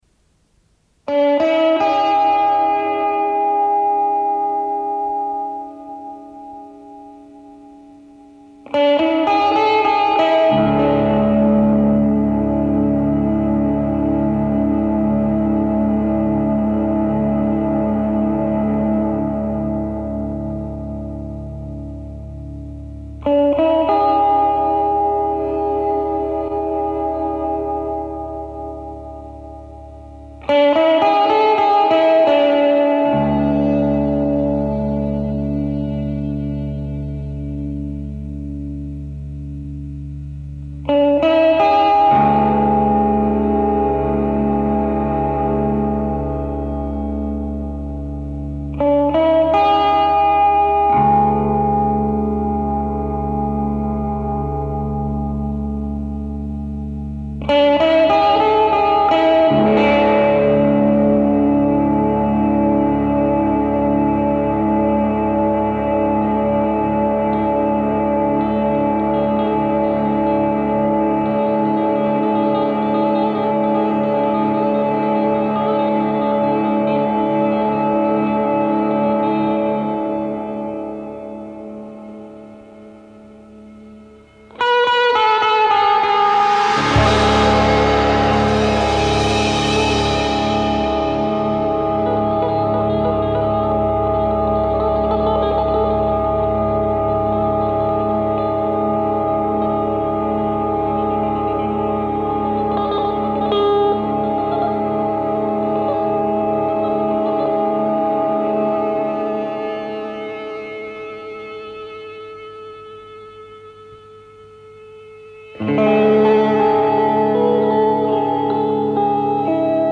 lo-fi